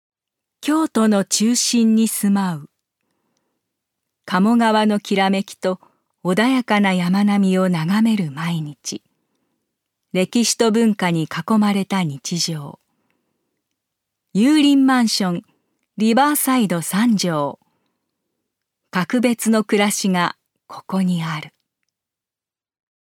女性タレント
ナレーション３